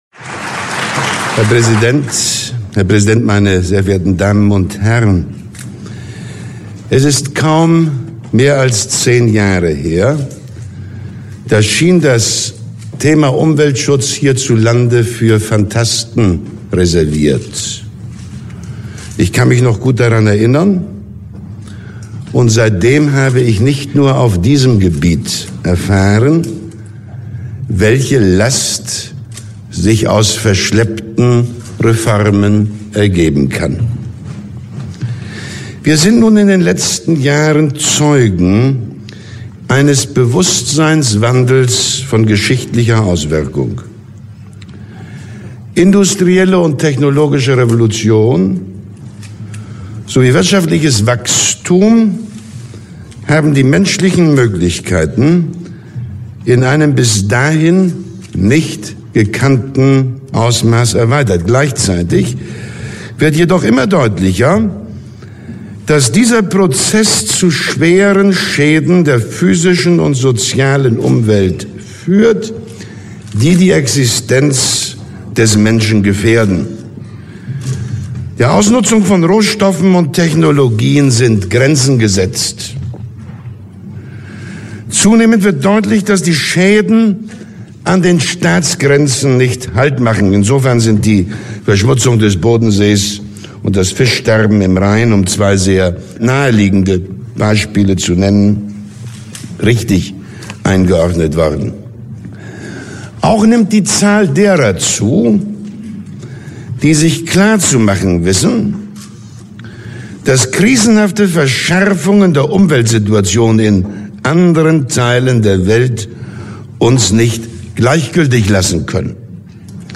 Rede-Willy-Brandt-Lindau-1972.mp3